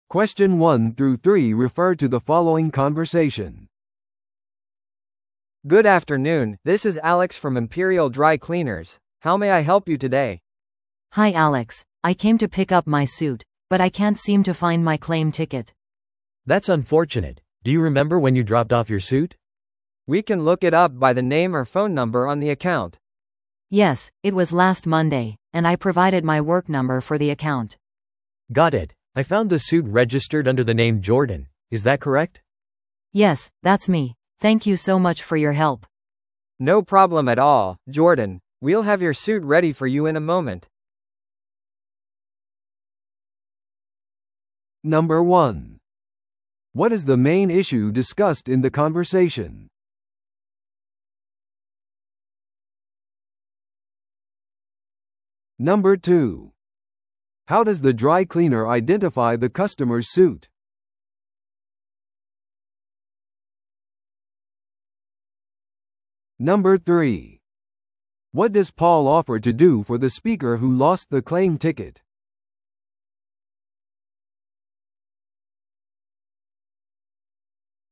No.1. What is the main issue discussed in the conversation?